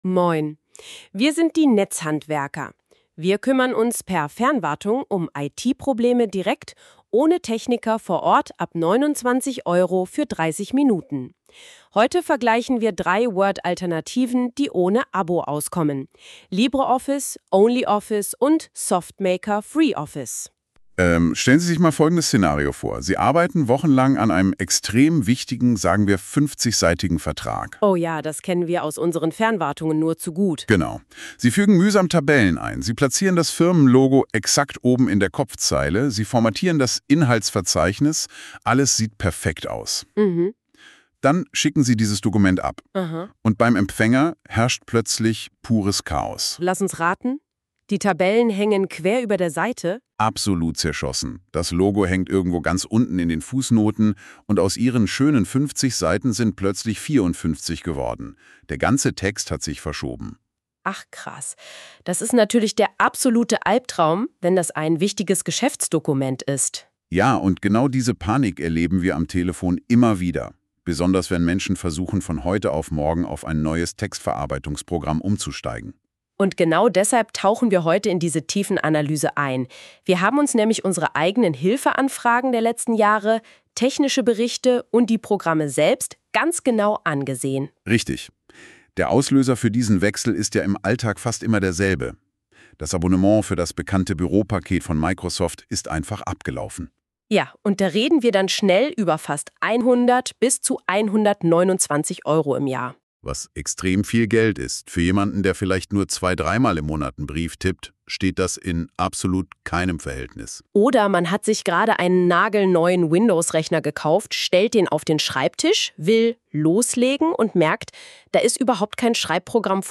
Die wichtigsten Punkte dieses Artikels als lockere Dialog-Folge – ideal fürs Pendeln oder Kochen. Die beiden Stimmen sind KI-generiert.